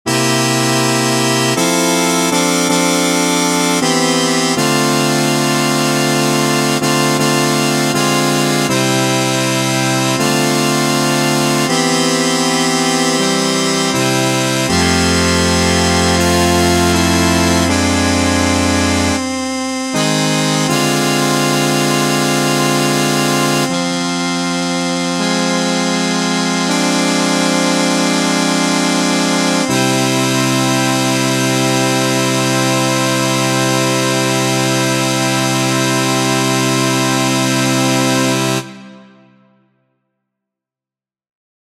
How many parts: 4
Type: Barbershop
All Parts mix: